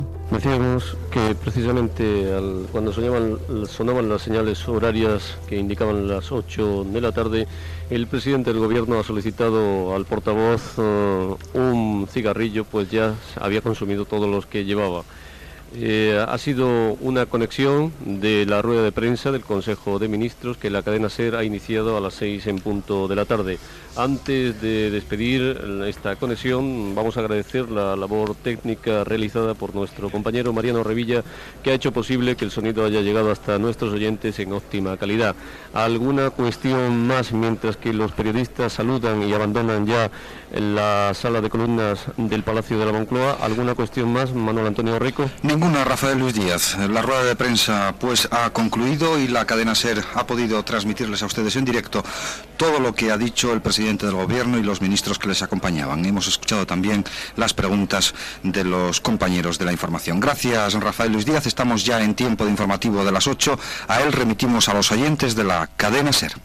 Transmissió des del Palacio de la Moncloa de la roda de premsa després del primer Consell de Ministres presidit per Felipe González.
Comiat de la transmissió des del Palacio de la Moncloa i inici de l'"Informativo de las ocho"
Informatiu